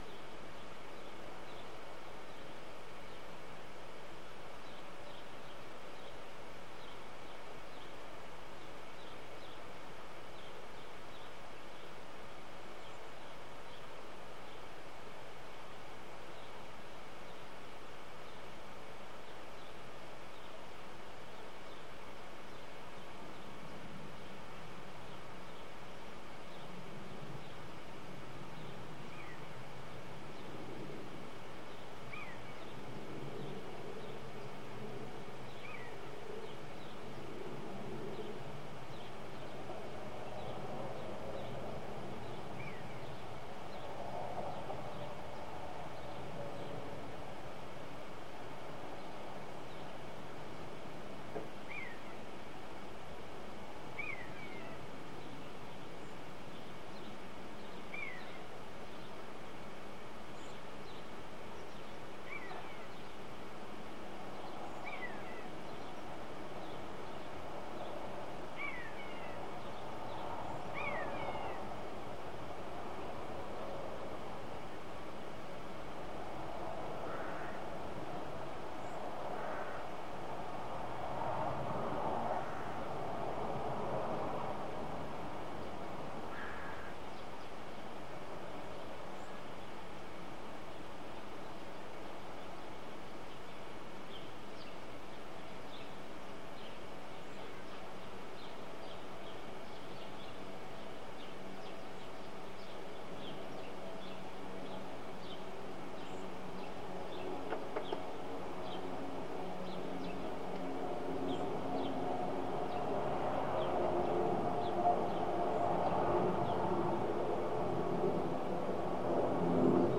Audio Aufnahme vom 05.02.2020 11:00-12:00 Uhr: BER Geräusch Aufnahme 1h |